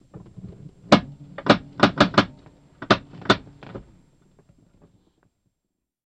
BMW Parking Brake, Set Very Slow